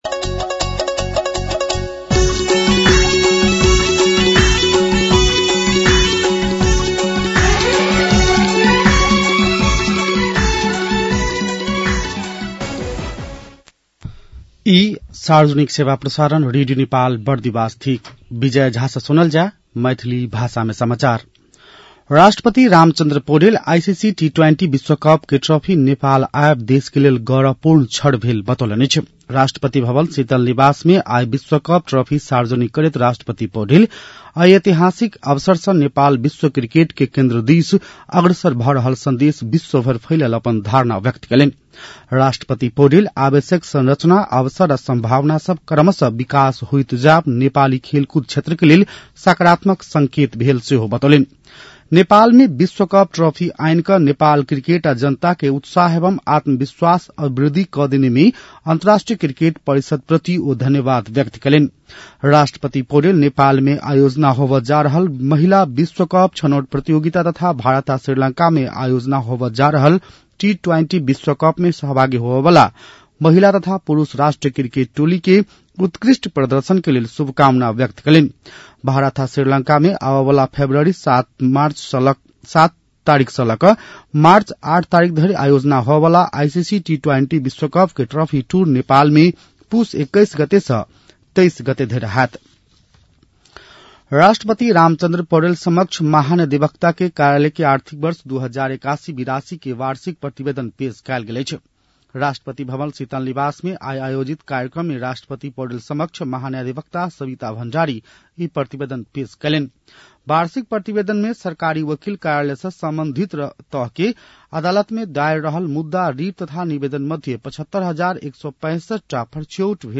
मैथिली भाषामा समाचार : २१ पुष , २०८२
6.-pm-maithali-news-1-1.mp3